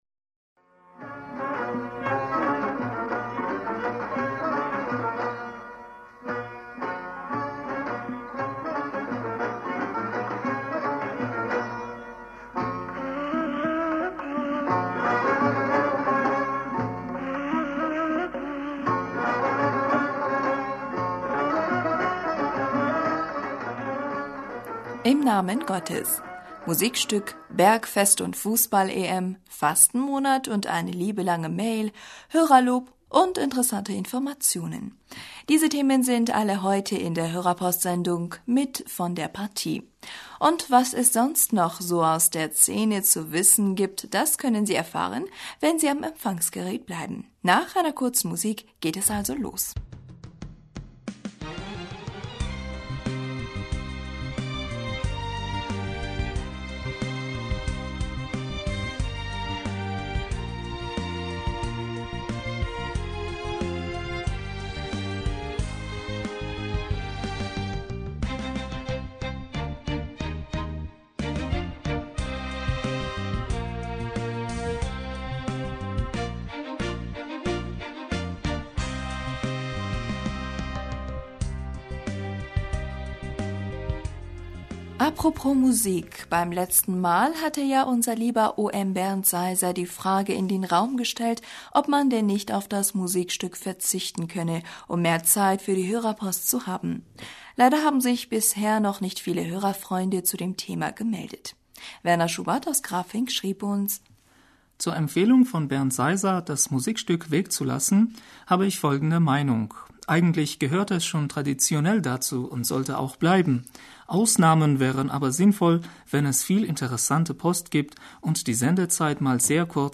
Hörerpostsendung am 03. Juli 2016 Bismillaher rahmaner rahim - Musikstück, Bergfest und Fußball-EM, Fastenmonat und eine liebe lange Mail, Hörerlo...